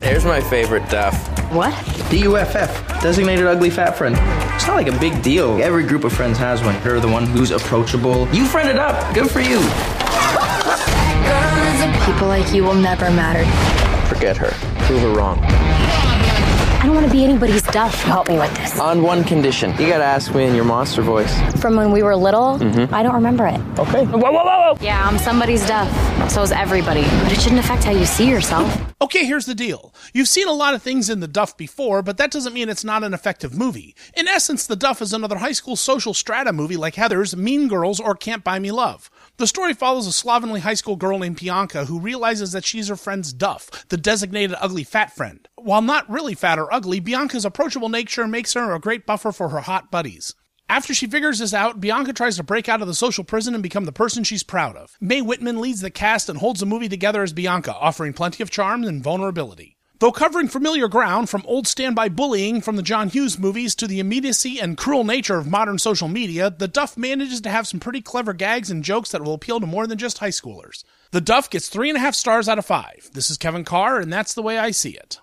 ‘The Duff’ Movie Review